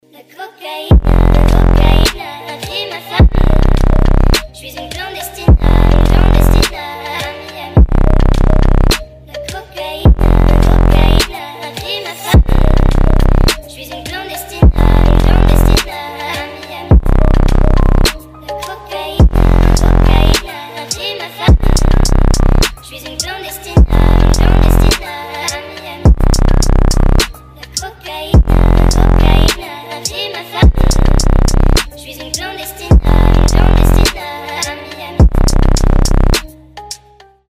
• Качество: 320, Stereo
мощные басы
ремиксы